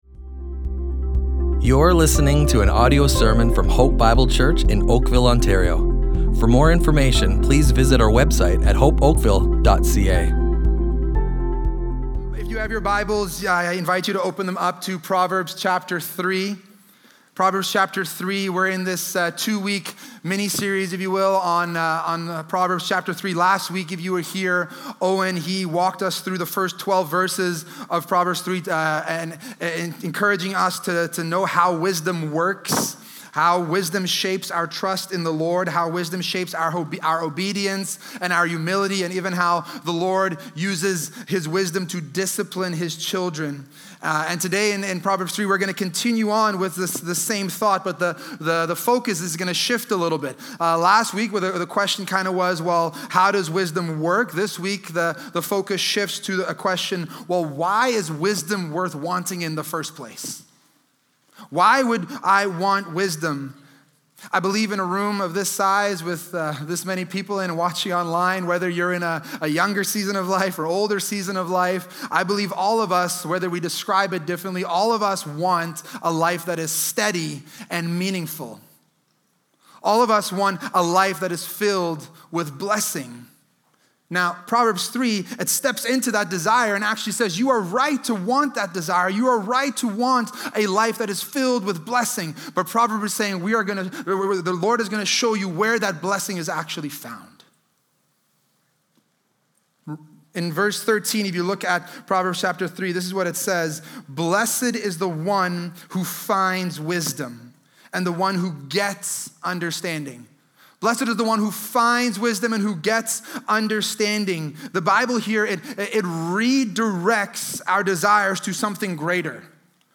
Hope Bible Church Oakville Audio Sermons The Unparalleled Blessing of Wisdom // A Treasure Without Equal!